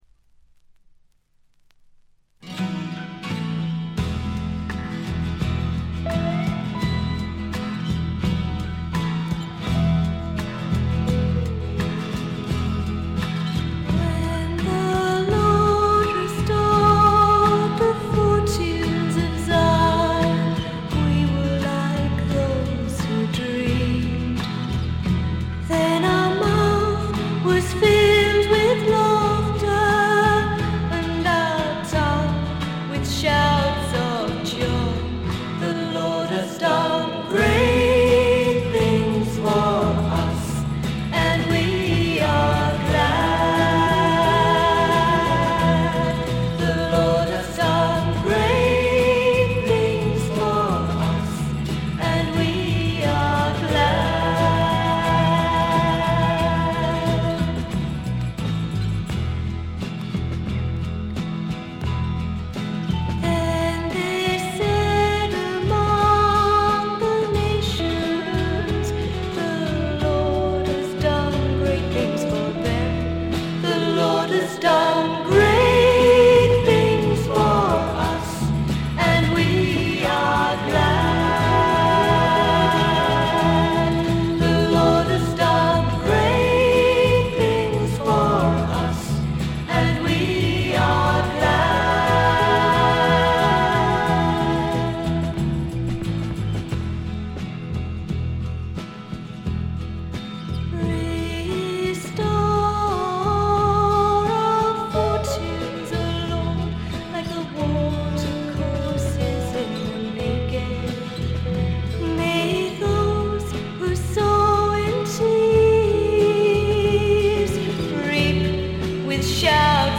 妖精フィメール入り英国ミスティック・フォーク、ドリーミー・フォークの傑作です。
霧深い深山幽谷から静かに流れてくるような神秘的な歌の数々。
それにしても録音の悪さが幸いしてるのか（？）、この神秘感は半端ないです。
試聴曲は現品からの取り込み音源です。